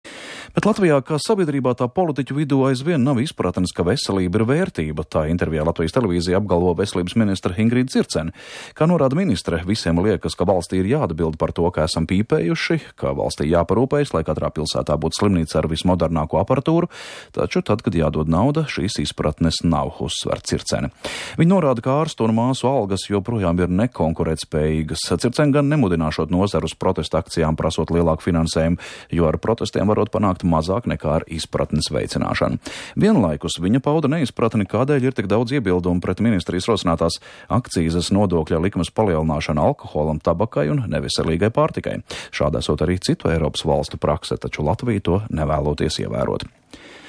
• 10.09.2013. (Latvijas Radio1).